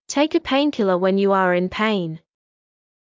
ﾃｲｸ ｱ ﾍﾟｲﾝｷﾗｰ ｳｪﾝ ﾕｰ ｱｰ ｲﾝ ﾍﾟｲﾝ